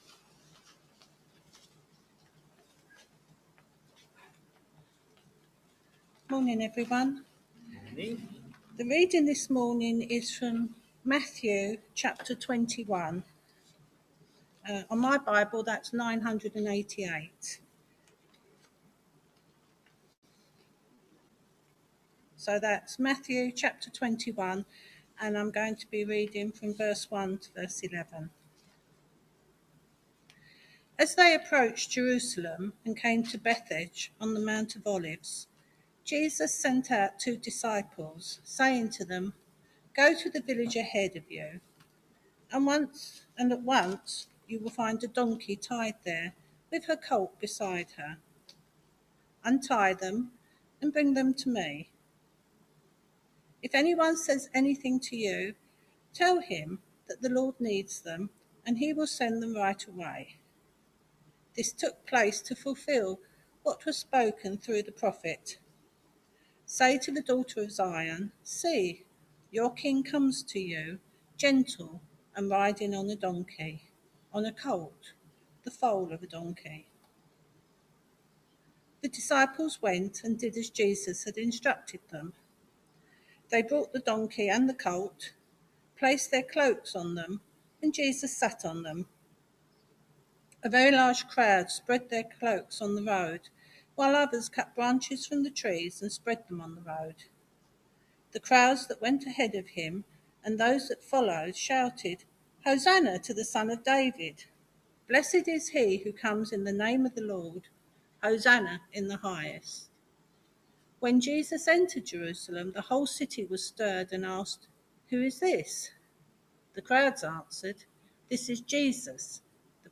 Matthew 21v1-11 Service Type: Sunday Morning Service Topics